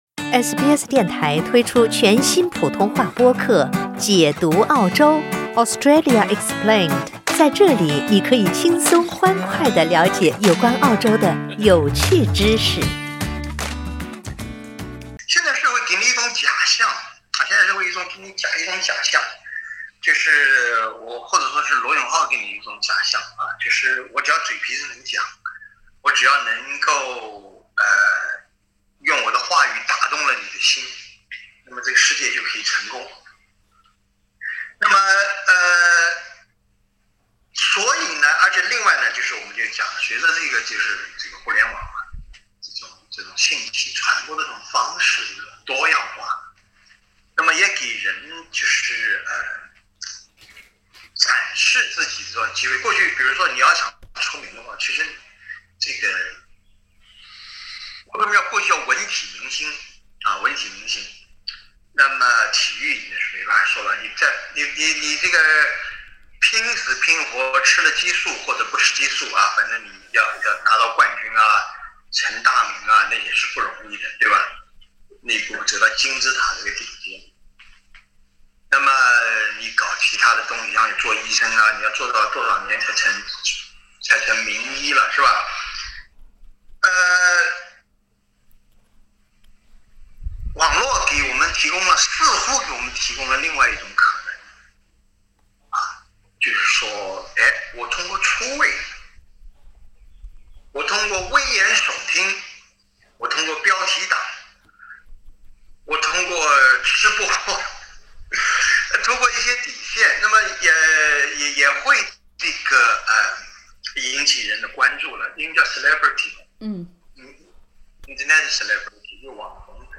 网红直播带货你看过吗？会买吗？（点击封面图片，收听完整对话）